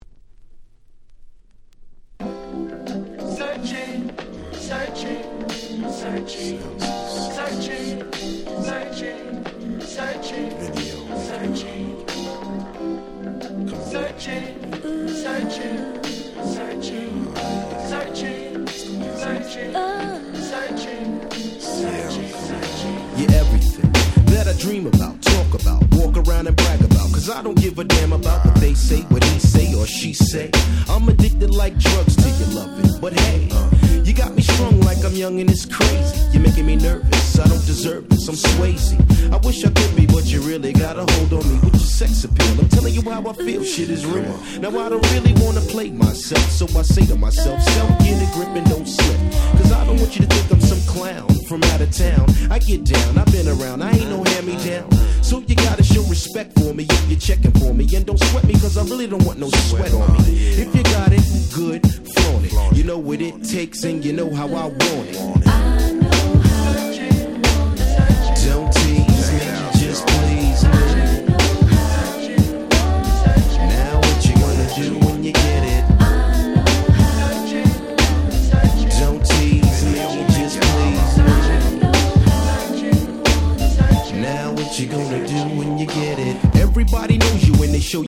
95' Super Nice Hip Hop !!